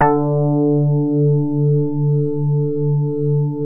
JAZZ MID  D2.wav